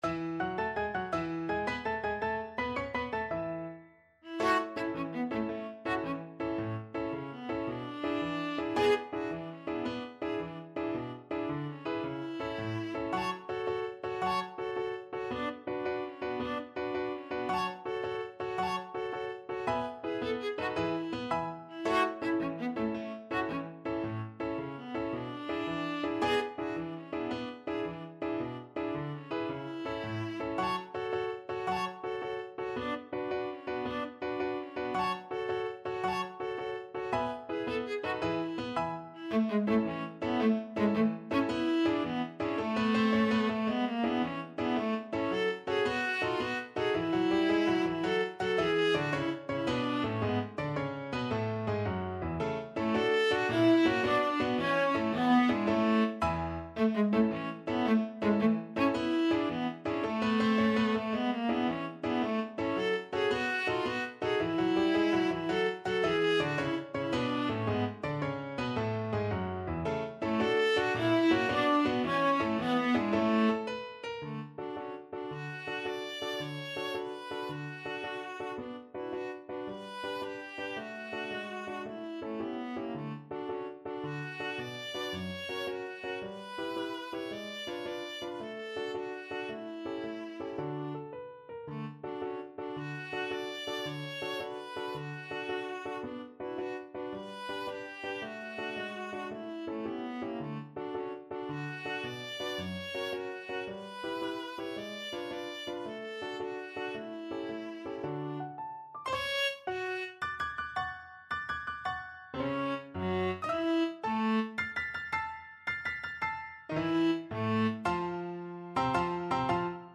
Free Sheet music for Viola
Viola
A major (Sounding Pitch) (View more A major Music for Viola )
6/8 (View more 6/8 Music)
Classical (View more Classical Viola Music)